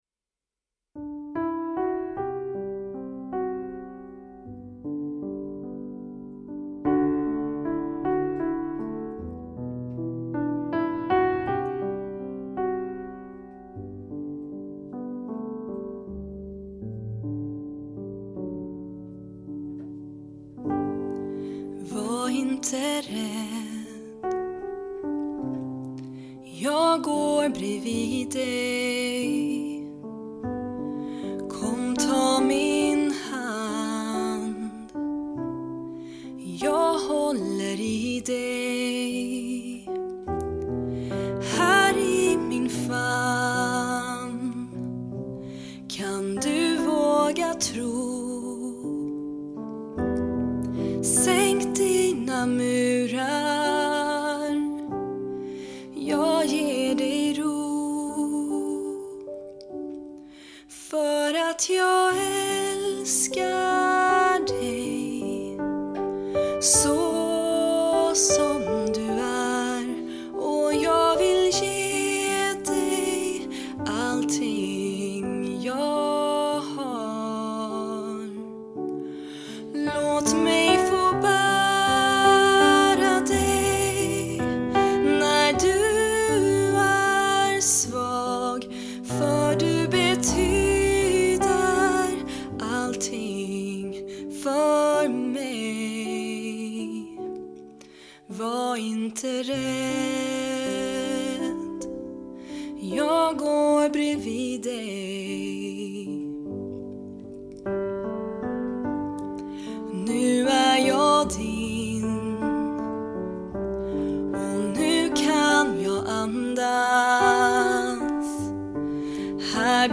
Piano/gitarr
Fiol